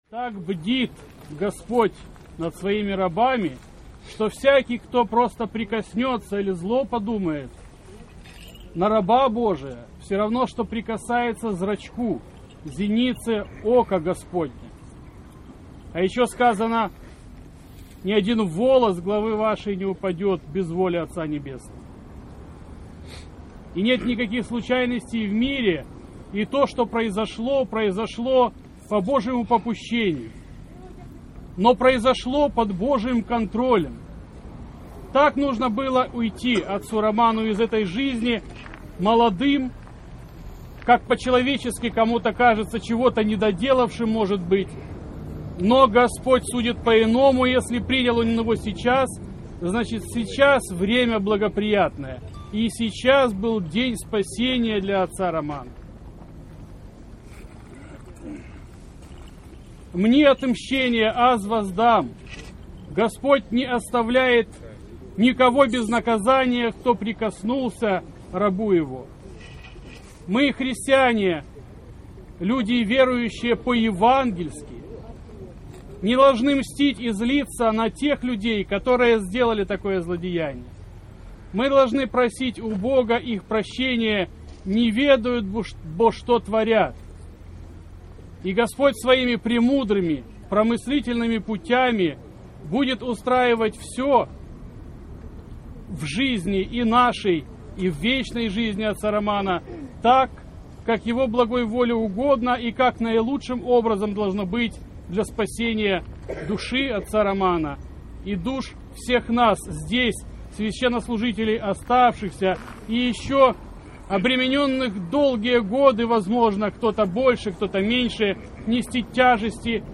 После одпуста владыка Феодосий обратился к собравшимся со словами проповеди и зачитал письмо-соболезнование Предстоятеля Украинской Православной Церкви матери и жене покойного.